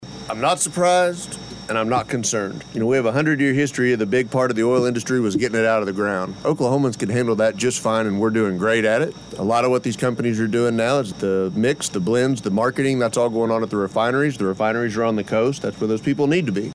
Fresh from the Bartlesville Chamber of Commerce "Eggs and Issues" event on Friday, where Representative John B. Kane, Senator Julie Daniels, and Representative Judd Strom met with constituents, the trio joined us in studio for KWON Radio's CAPITOL CALL program powered by Phillips 66